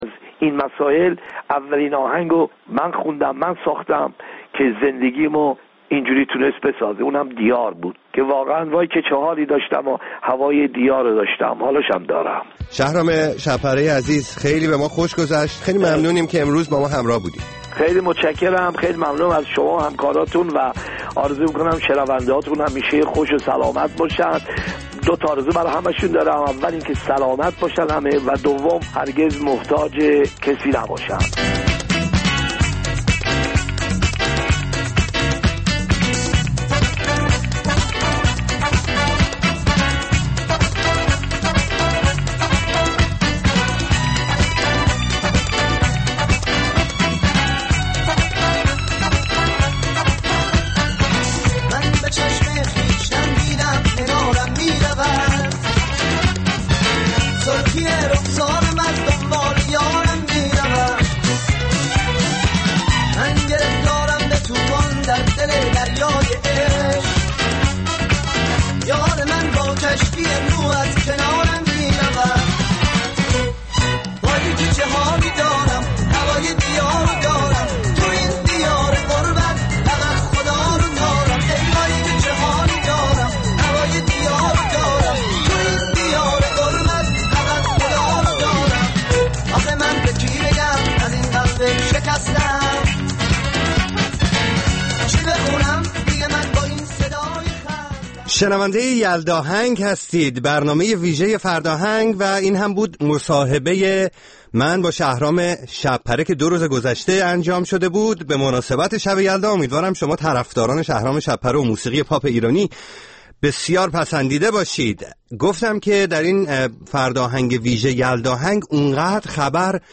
برنامه زنده موسیقی